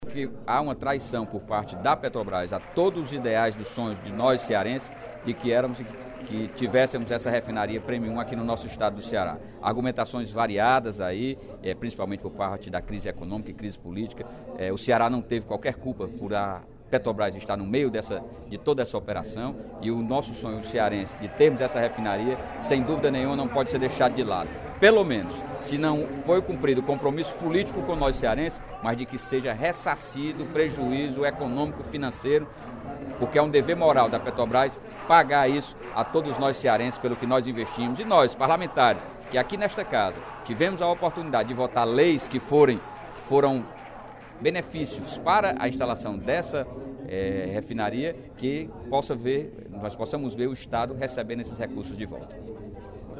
O deputado Sérgio Aguiar (PDT) cobrou, no primeiro expediente da sessão plenária desta terça-feira (05/07),  o ressarcimento do prejuízo financeiro causado ao Estado com a desistência da instalação da Refinaria Premium no Ceará.